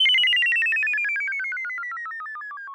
08 fx hit.wav